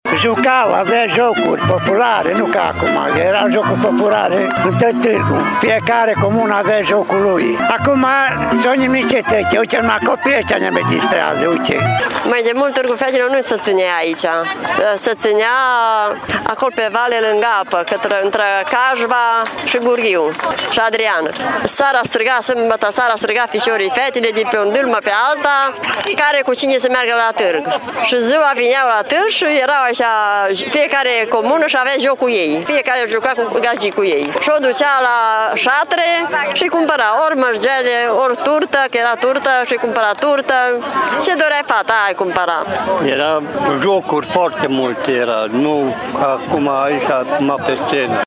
Bătrânii satului îşi amintesc de emoţia cu care aşteptau târgul, de cadourile pe care băieţii le cumpărau fetelor şi, mai ales, de căsătoriile stabilite atunci: